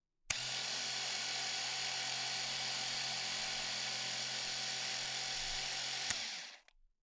电动剃须刀；修剪器 " 电动剃须刀7移除刀片盖
描述：电动剃须刀的记录（参见特定类型剃须刀的标题）。 于2018年7月19日以RØDENT2A录制。
Tag: 剃须 剃刀刃 胡须 electricrazor 卫生 剃光 电动 剃须刀 刮胡子